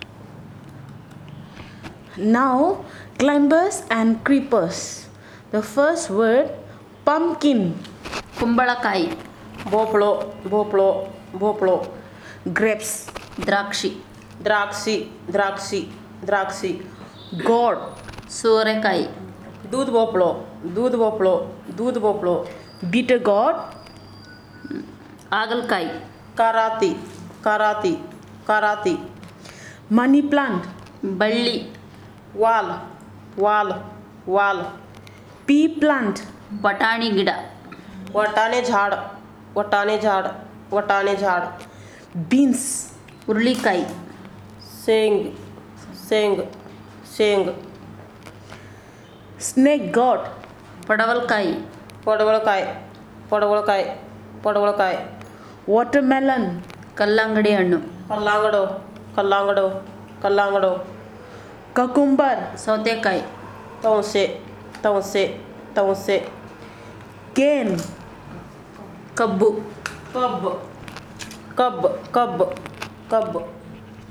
Elicitation of words about climbers and creepers
NotesThis is an elicitation of words about climbers and creepers using the SPPEL Language Documentation Handbook in order to gather data.